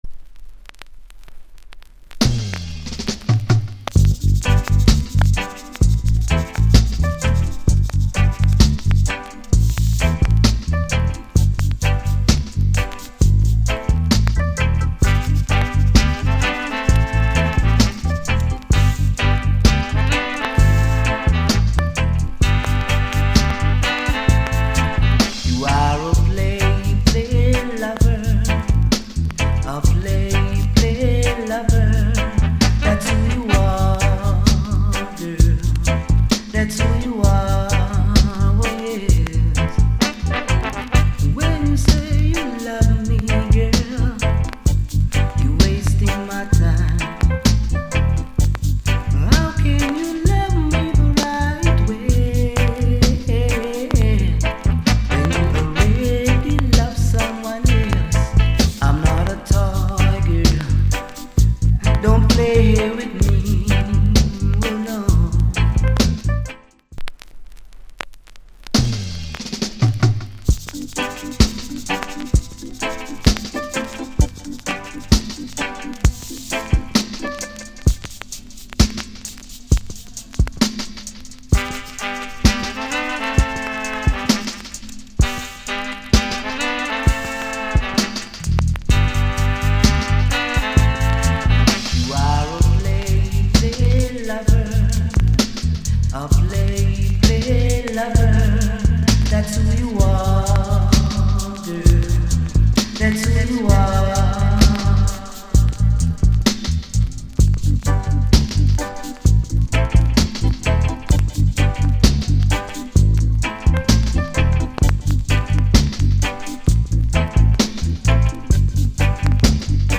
* Mellow Jamaican Lovers